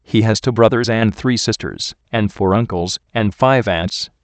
◇音声は日本語、英語ともに高音質のスピーチエンジンを組み込んだ音声ソフトを使って編集してあります。
音声−答え